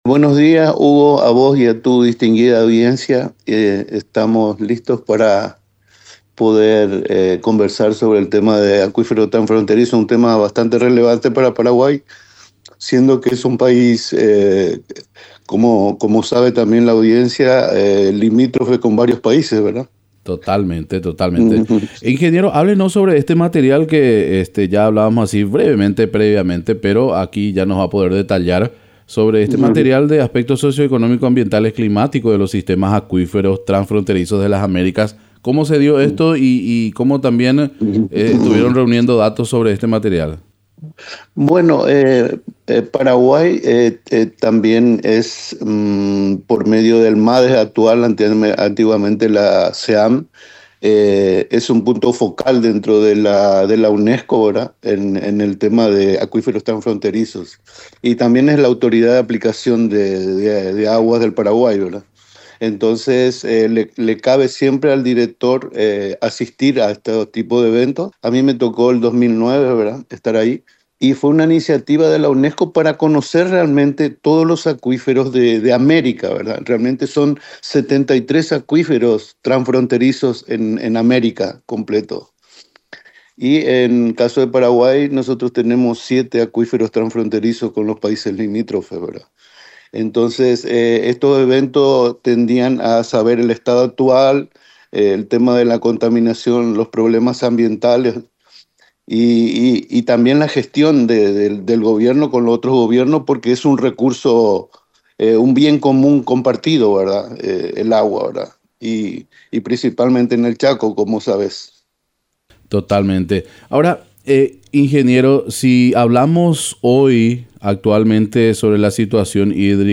Entrevistas / Matinal 610